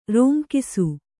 ♪ rōnkisu